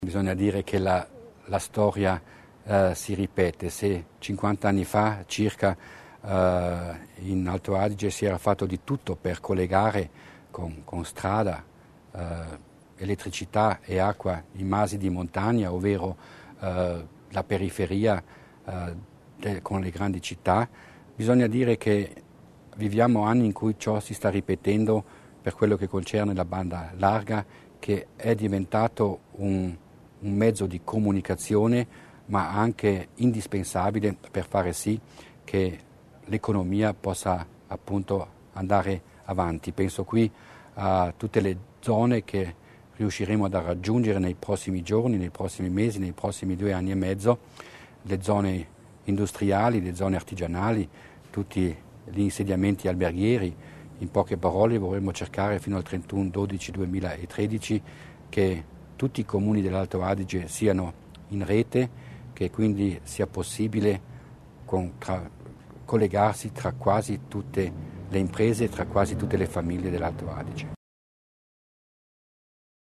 Durante il colloquio di metà legislatura l'assessore Mussner ha parlato anche di banda larga partendo da una citazione dell'ex presidente Silvius Magnago, che negli anni '60 aveva indicato come obiettivo l'allacciamento di tutta la popolazione alla rete idrica, elettrica e stradale.